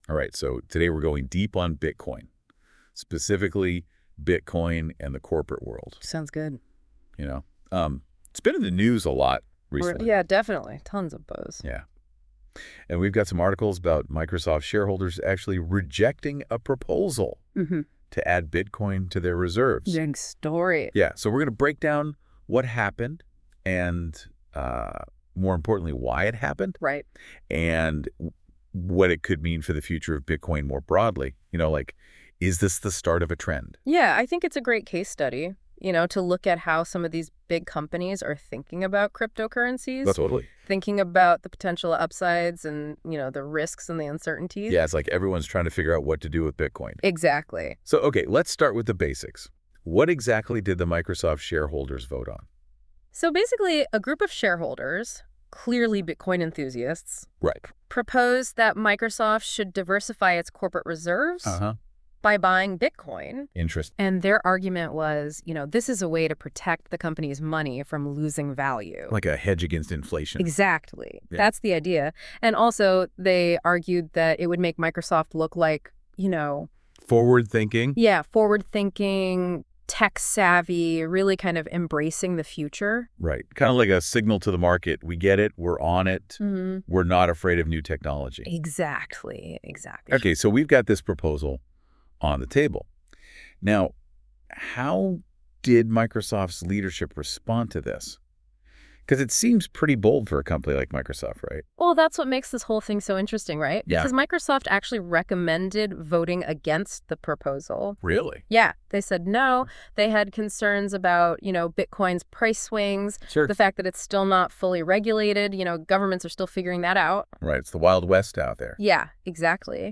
Podcast Discussion: Deep Dive Into This Article.